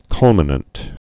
(kŭlmə-nənt)